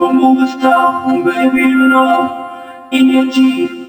VOXVOCODE1-L.wav